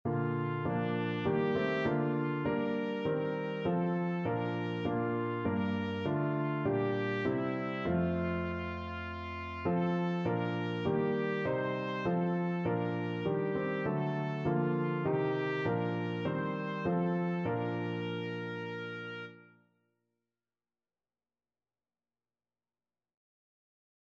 4/4 (View more 4/4 Music)
Classical (View more Classical Trumpet Music)